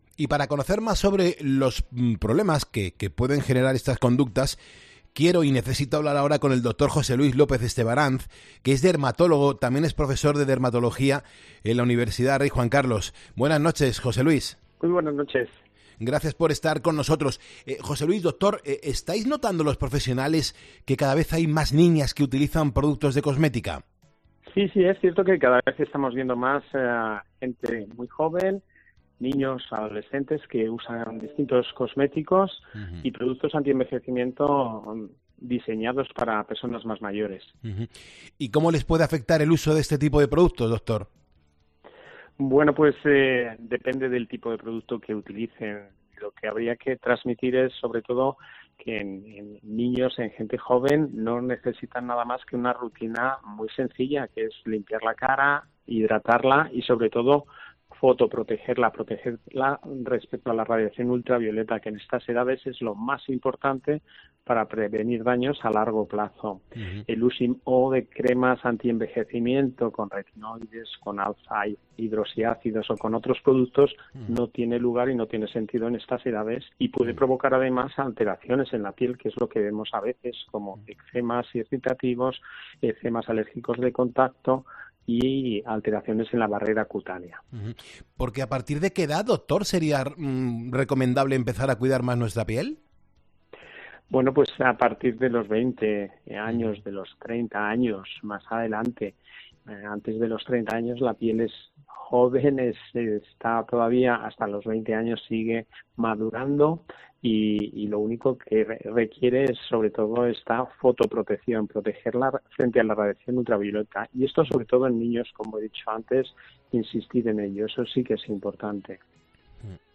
Un dermatólogo explica qué les sucede a las niñas menores de edad por usar maquillaje: "Antes de los 30 años"